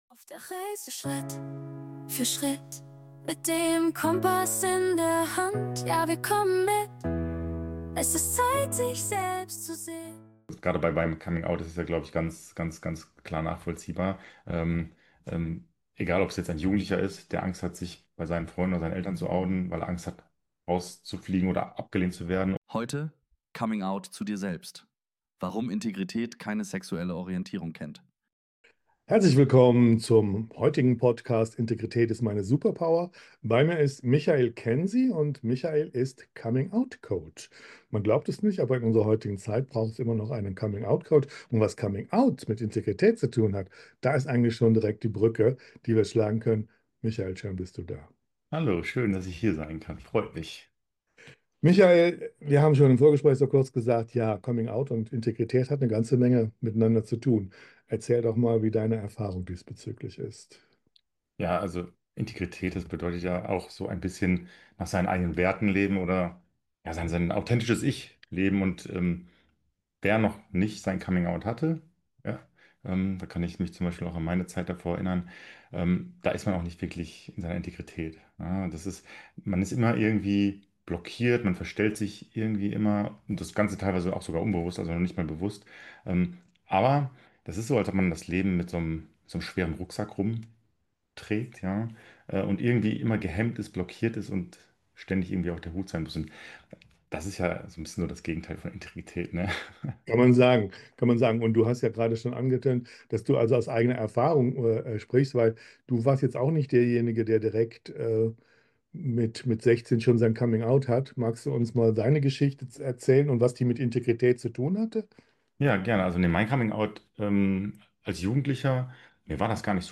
Und zu lieben, ohne Bedingungen zu stellen. 3 wichtige Punkte aus dem Interview: 1. Coming-out ist ein Prozess, kein einmaliges Ereignis.